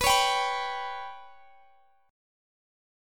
Listen to Bsus2#5 strummed